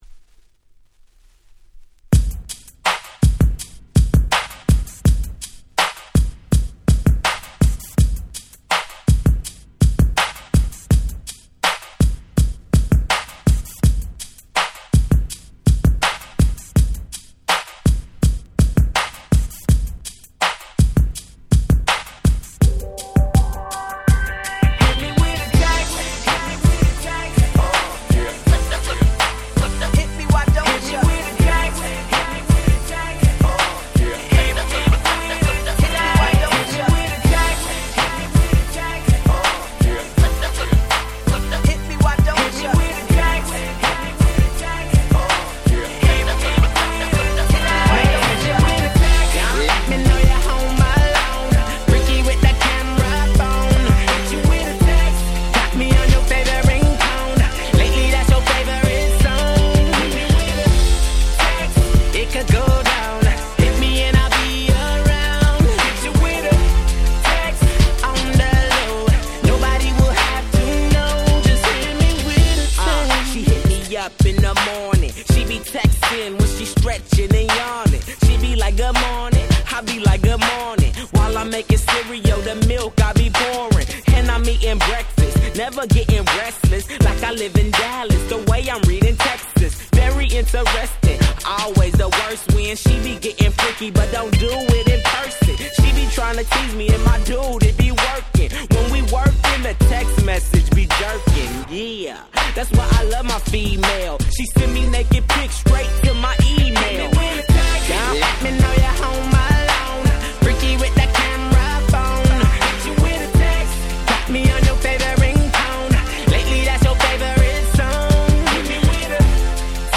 11' Smash Hit Hip Hop !!
キャッチー系